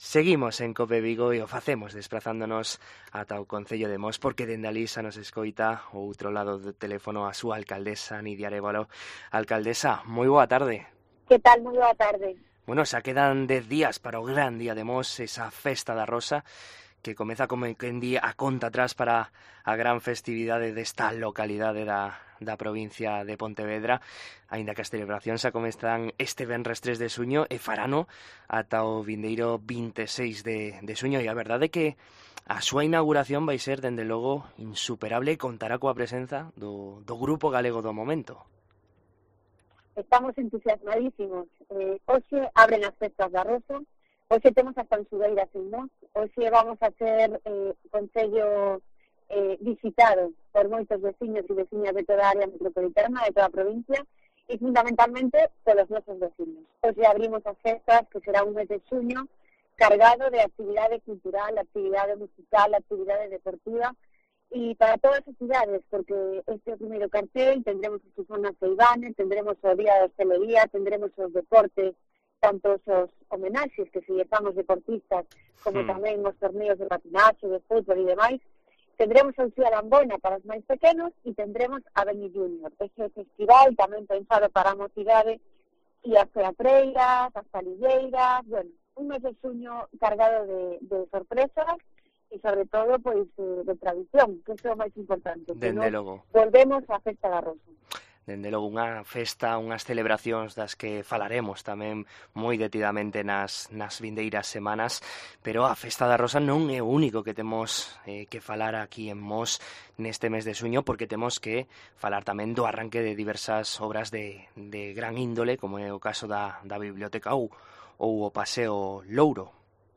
AUDIO: En COPE Vigo coñecemos a actualidade de Mos da man da sua alcaldesa, Nidia Arévalo